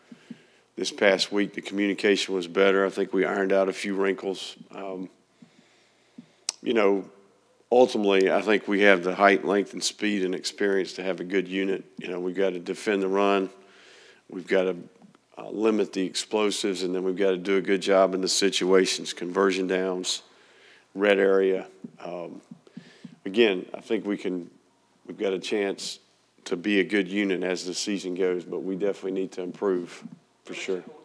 The Gators are fortunate to have two standout quarterbacks and he plans to use both in every game, Florida football coach Billy Napier said at Monday’s news conference.